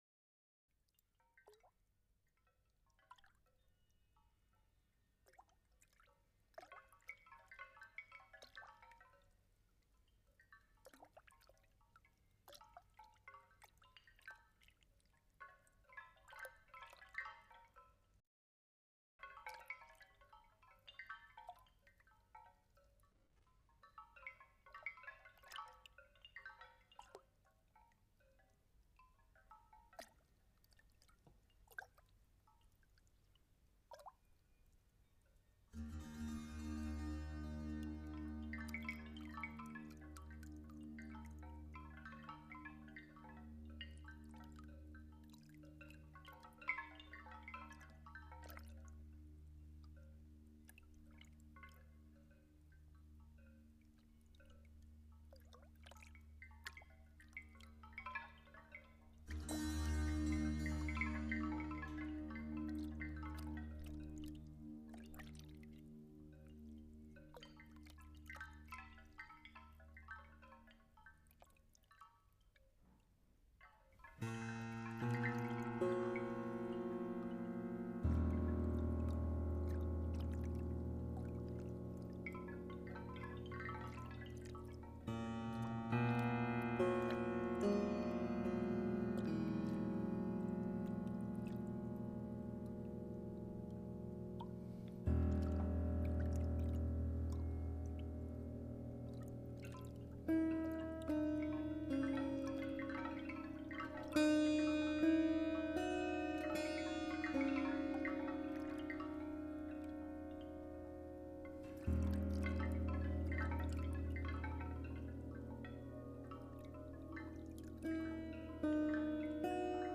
健康养生音乐 > 西方冥想音乐
民谣吉他、古典吉他、电吉他、竖琴、曼陀林、贝斯、钢琴、木管钟、水桶、长笛